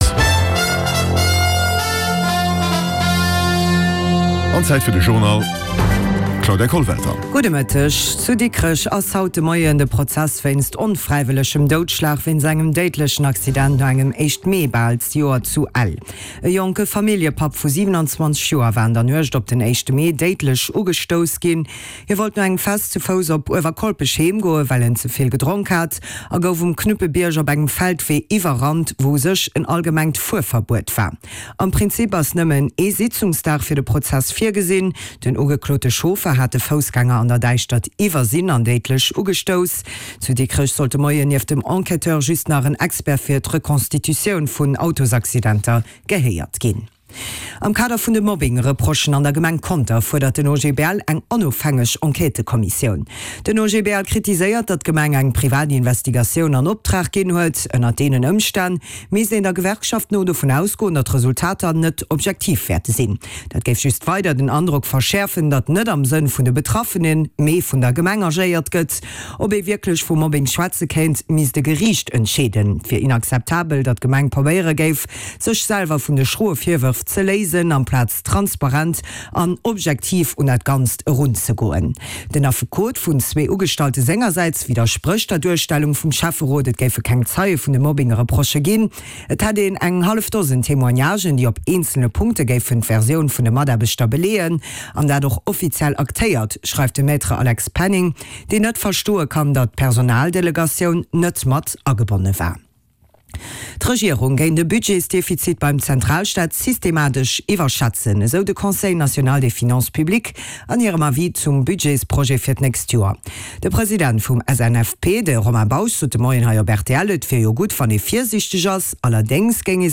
De grousse Journal vun RTL Radio Lëtzebuerg, mat Reportagen, Interviewën, Sport an dem Round-up vun der Aktualitéit, national an international